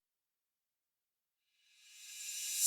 rev_crash-1